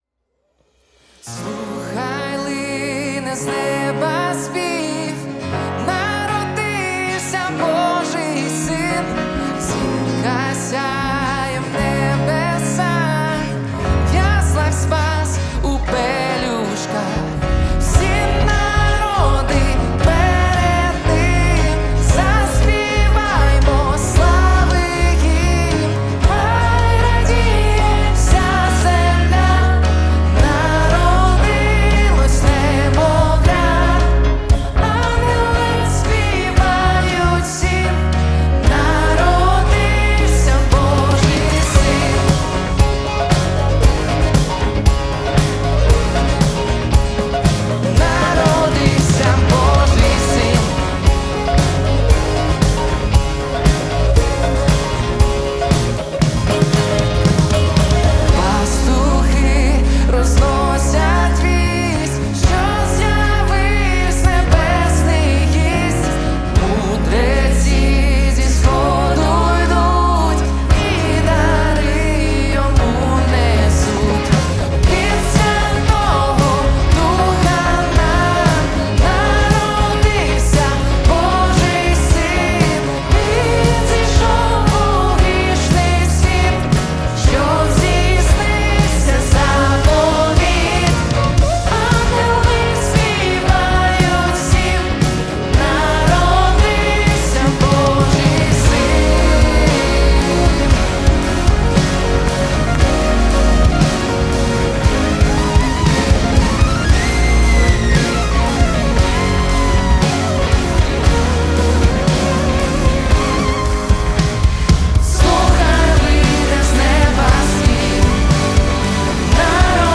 775 просмотров 3 прослушивания 0 скачиваний BPM: 116